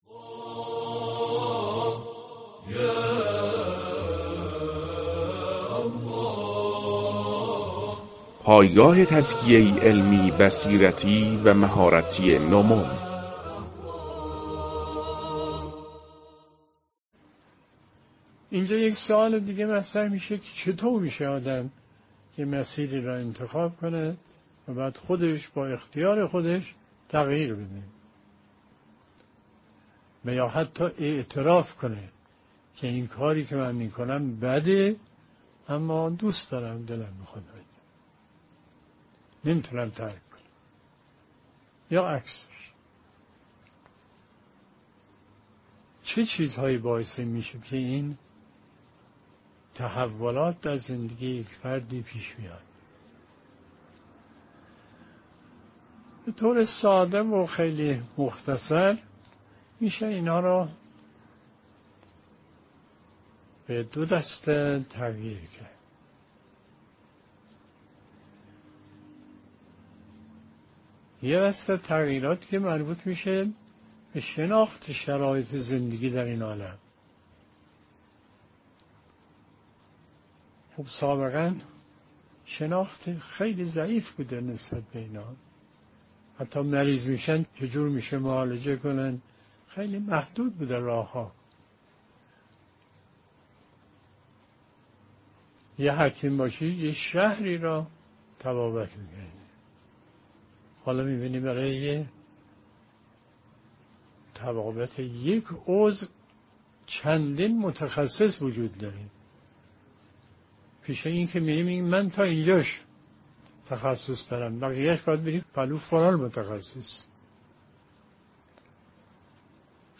🔰 بیانات آیت الله مصباح درباره تحول در زندگی